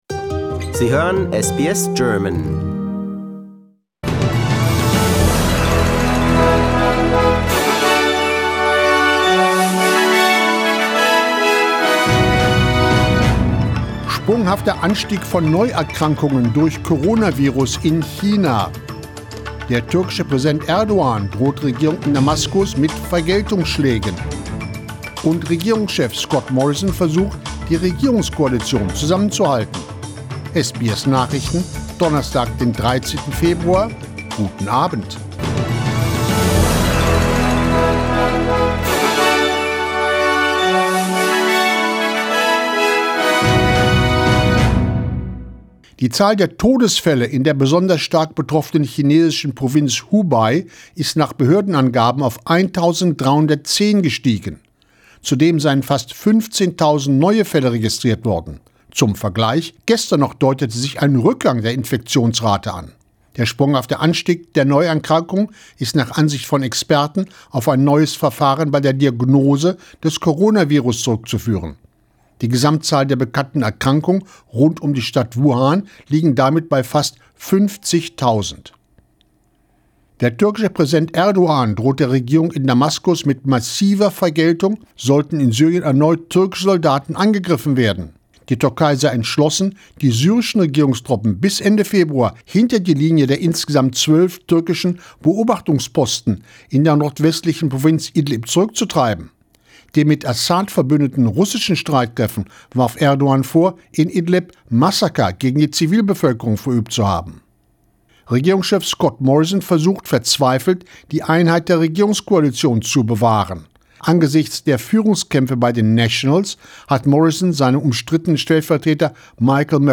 SBS Nachrichten, Donnerstag 13.02.20